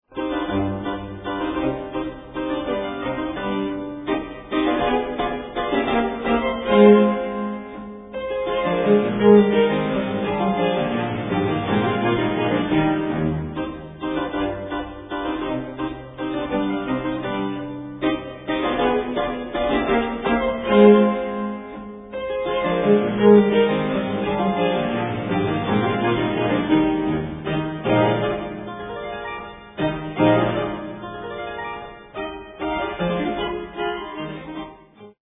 for Violin, Cello and Harpsichord